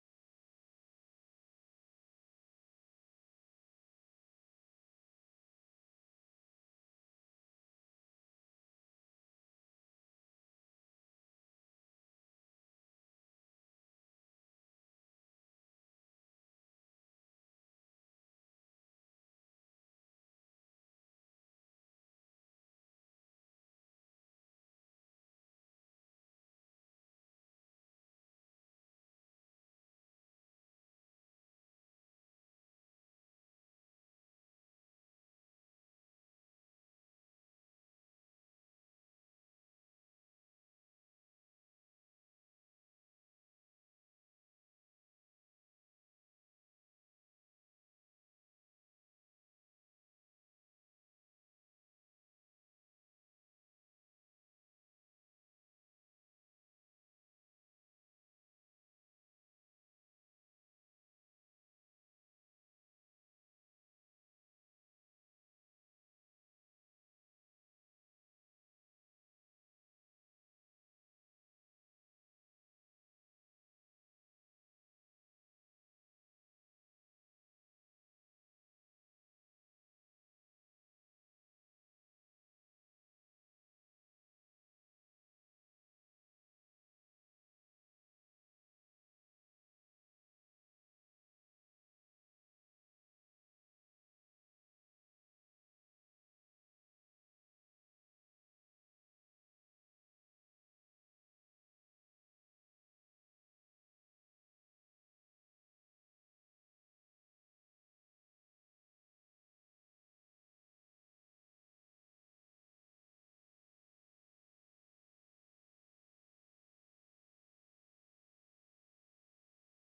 ライブ・アット・バルボア・ジャズ・クラブ、マドリード、スペイン 12/12/1979
海外マニアによるリマスター盤！！
※試聴用に実際より音質を落としています。